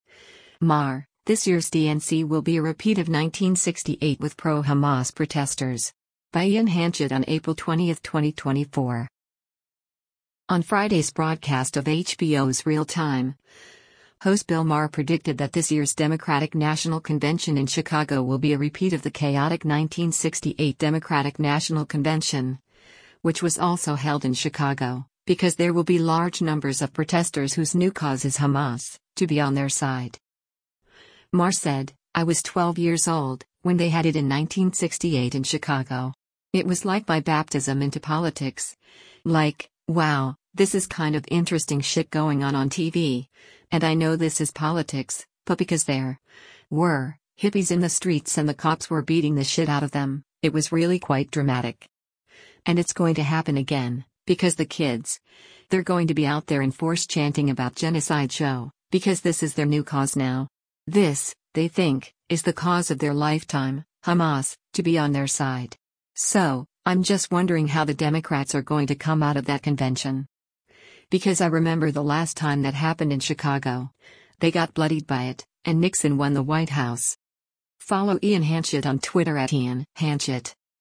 On Friday’s broadcast of HBO’s “Real Time,” host Bill Maher predicted that this year’s Democratic National Convention in Chicago will be a repeat of the chaotic 1968 Democratic National Convention, which was also held in Chicago, because there will be large numbers of protesters whose “new cause” is “Hamas, to be on their side.”